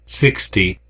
语音说出的数字组件0至999 " n60
描述：美国英语男声中用一组数字中的一个来构造从0到999的数字。
标签： 语音 英文 数字 美国 声乐 口语
声道立体声